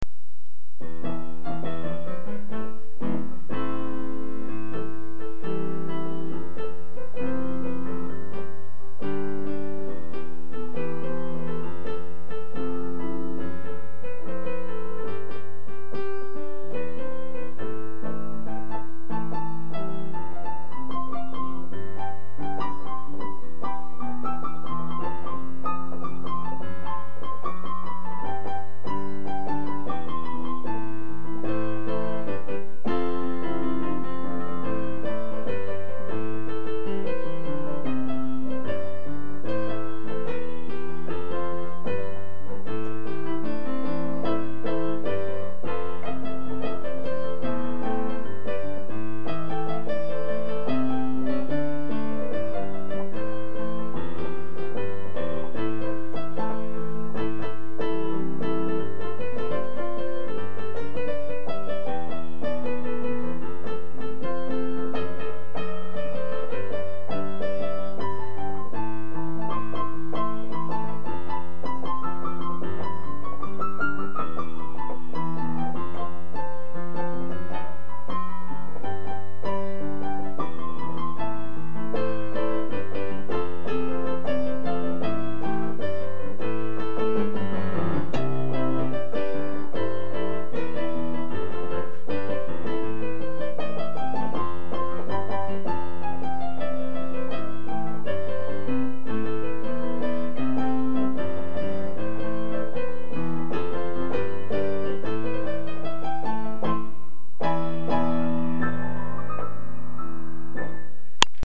יש מה לשפר באיכות ההקלטה, אבל סה"כ נחמד..
י אנונימי י הודעה אחרונה :) רק לדעתי, המקצב לא אחיד..